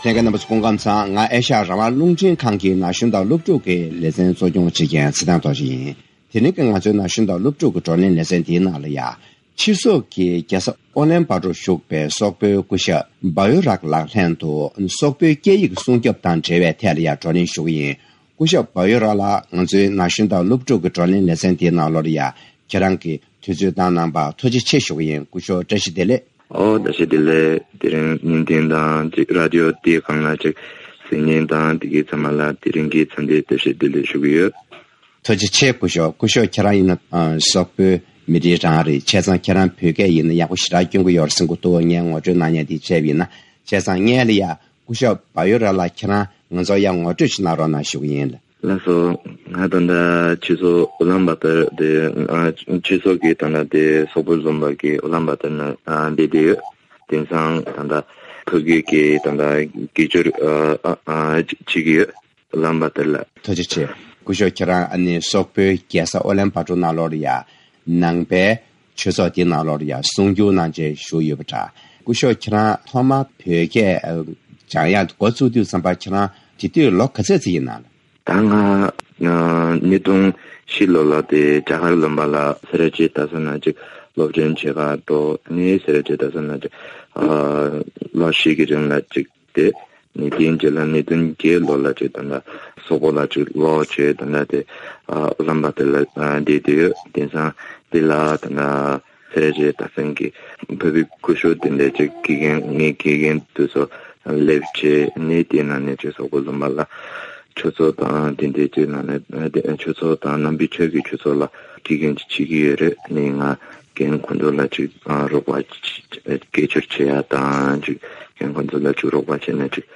ན་གཞོན་དང་སློབ་ཕྲུག་གི་བགྲོ་གླེང་ལེ་ཚན་འདིའི་ནང་དུ།